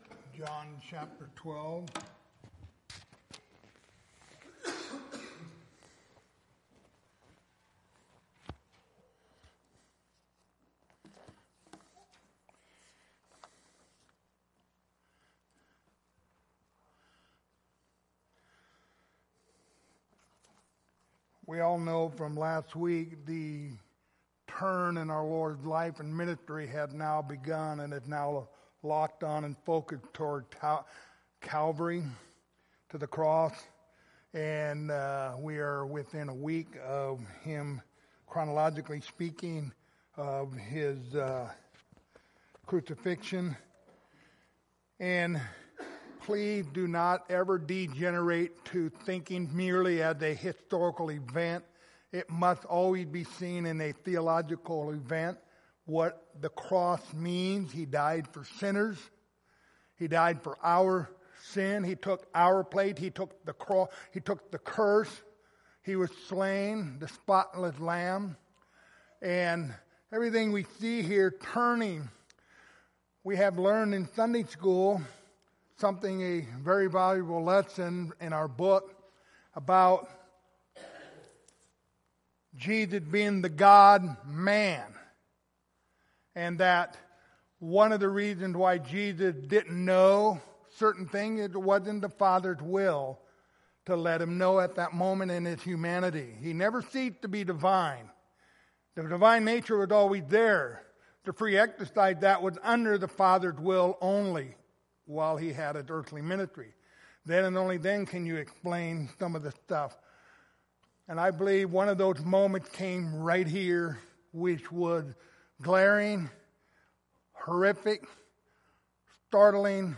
Passage: John 12:27-32 Service Type: Wednesday Evening